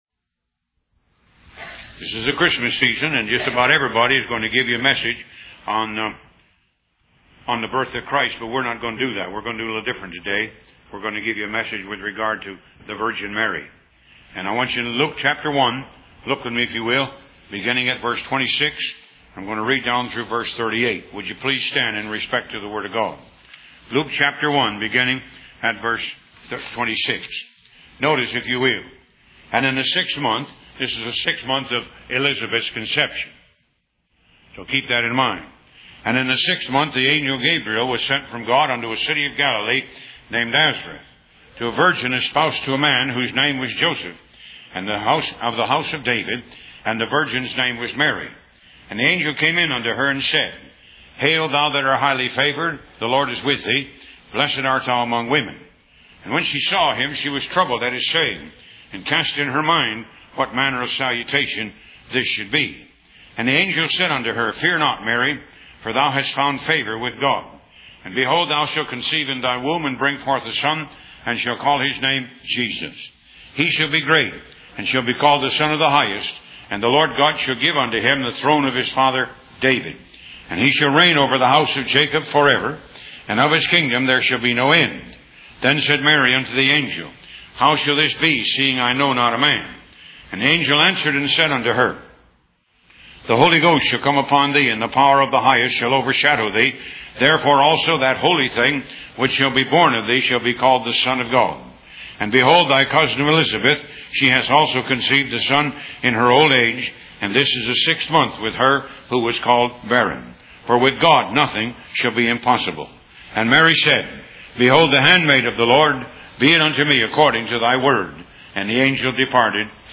Talk Show Episode, Audio Podcast, Moga - Mercies Of God Association and The Virgin Mary on , show guests , about The Virgin Mary, categorized as Health & Lifestyle,History,Love & Relationships,Philosophy,Psychology,Christianity,Inspirational,Motivational,Society and Culture